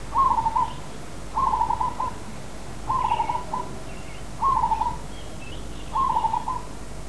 Here are a few samples of birds songs I recorded in the hotel's gardens.
Turtledove
turtledove.wav